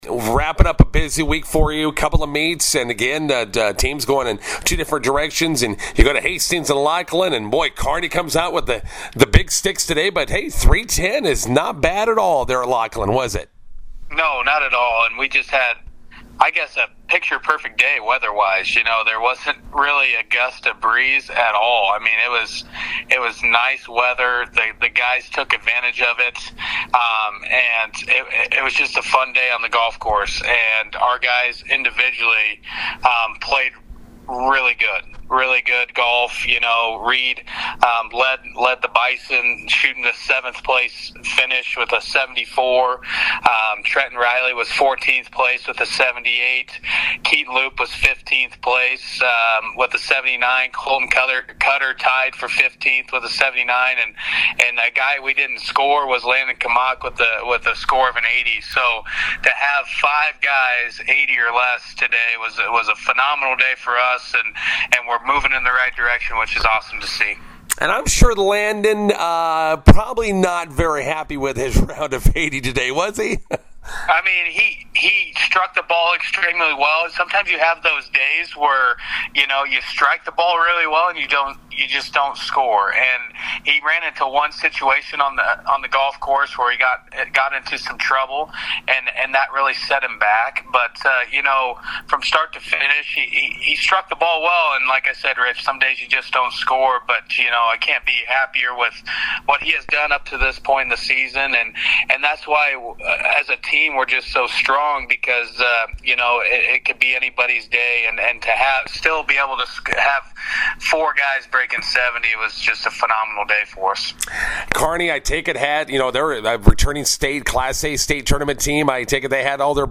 INTERVIEW: Bison golfers finish second at Hastings, JV's tenth at Curtis.